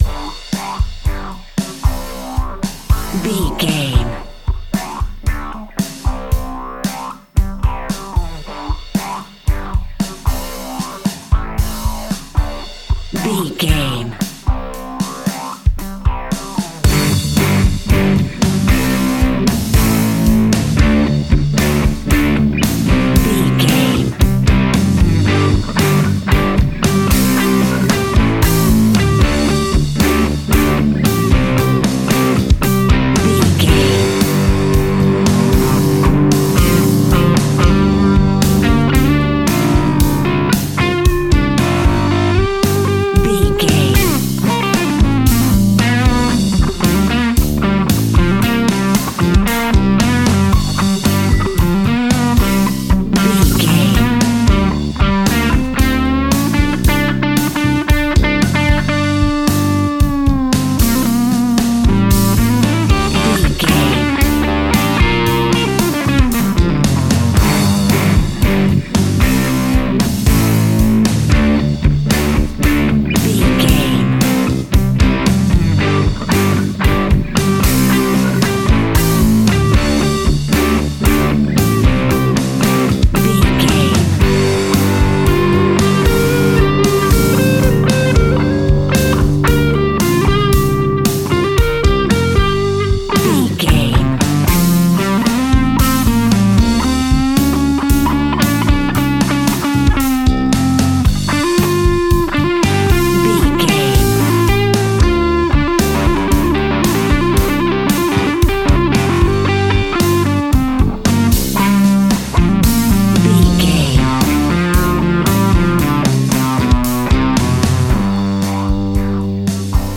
Epic / Action
Fast paced
Ionian/Major
hard rock
blues rock
instrumentals
Rock Bass
heavy drums
distorted guitars
hammond organ